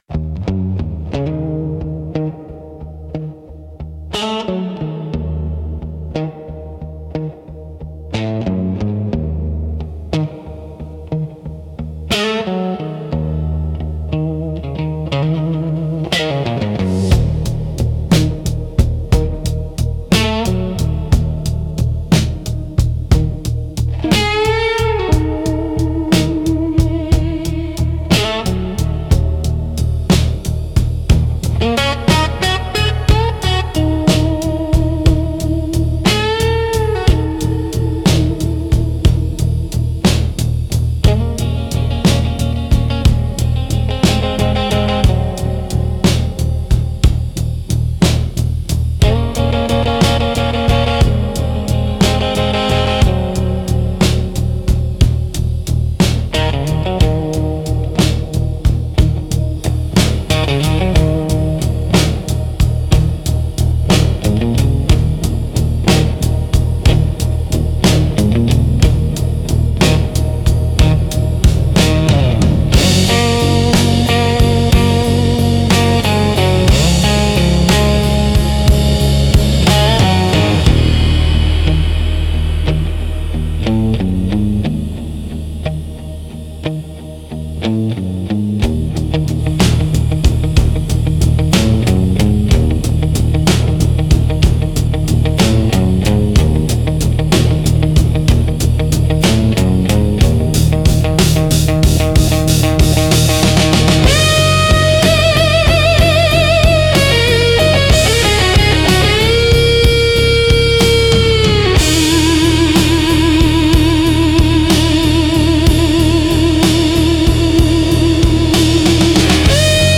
Instrumental - The Edges of Goodbye 2.39